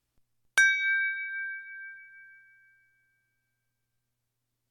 Wine glass 3
bell chime crystal ding wine-glass sound effect free sound royalty free Sound Effects